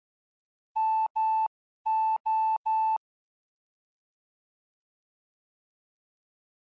Fox hunt beacons are identified by morse code signals.
Morse code identifiers of the foxes
finish MO — — — — — [ogg][mp3]